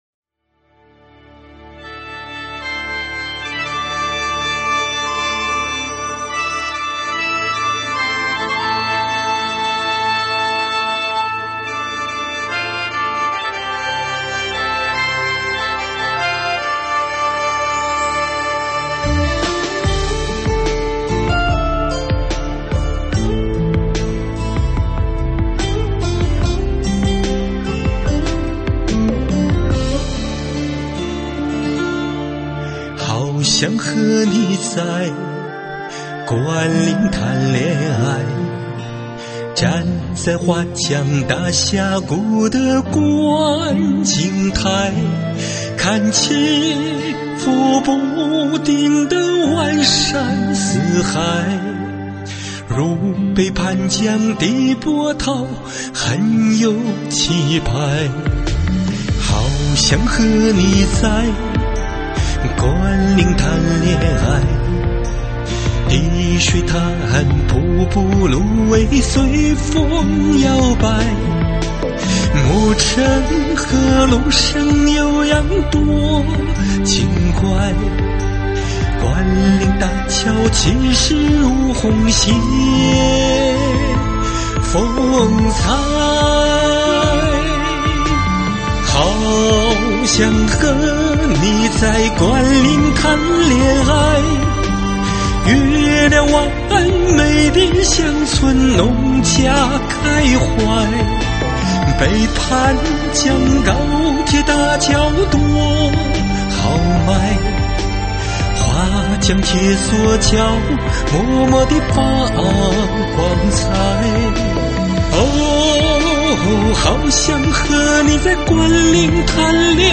中文舞曲